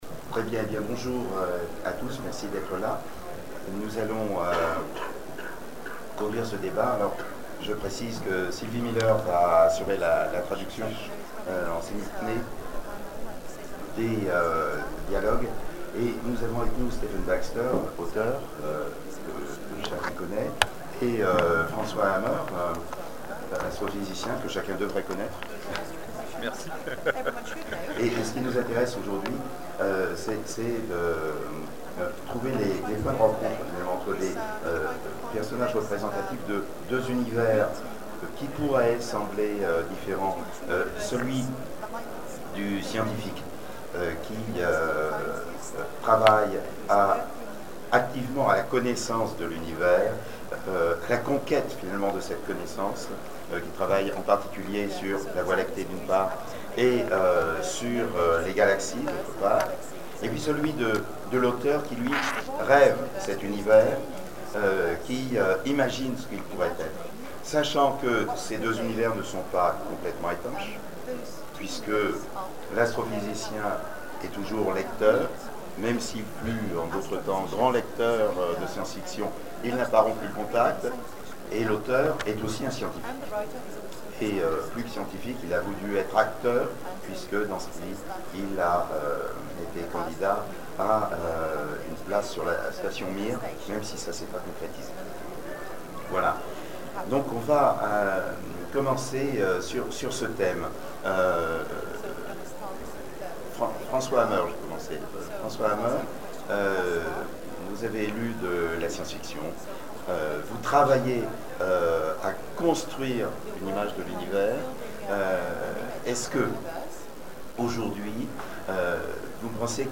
Zone Franche 2012 : Conférence Stephen Baxter, la science mise en fiction
(Attention, le son n'est pas très bon) Télécharger le MP3